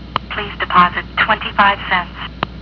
(.. on Elcotel Series 5 phones ..)
Human-like automated recording - .WAV/29KB